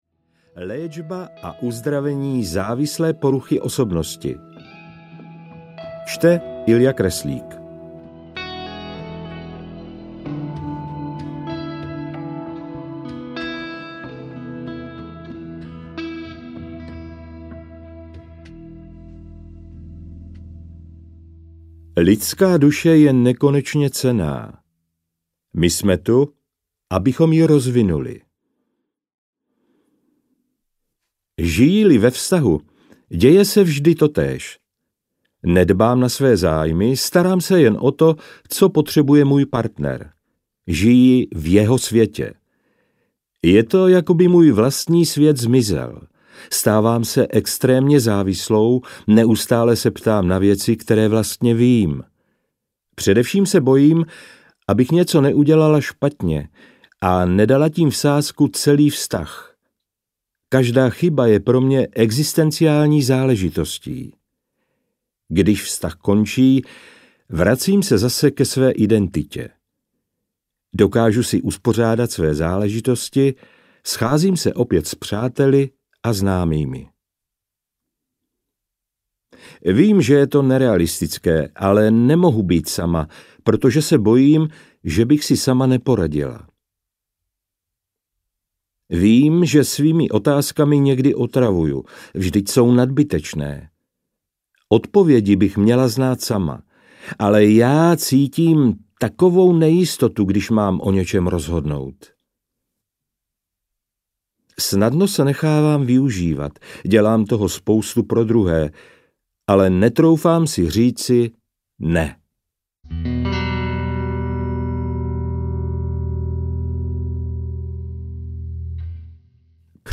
Závislé vztahy audiokniha
Ukázka z knihy